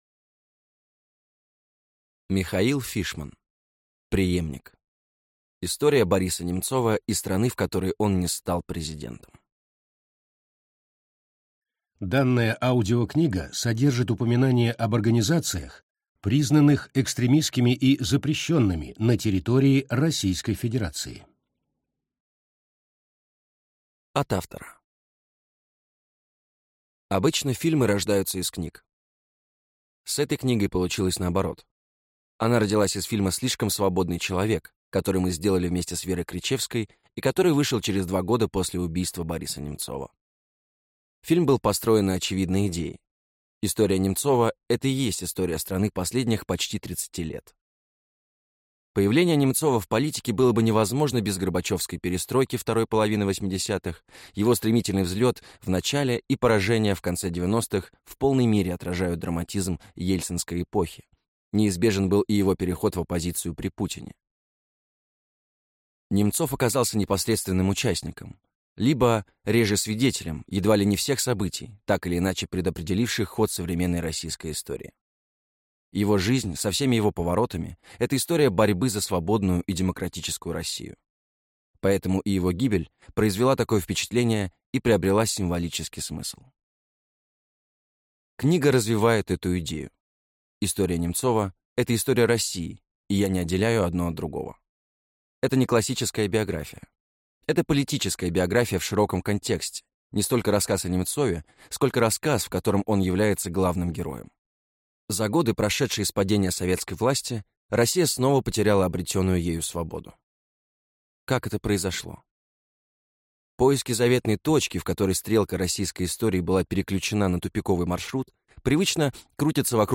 Аудиокнига Преемник. История Бориса Немцова и страны, в которой он не стал президентом | Библиотека аудиокниг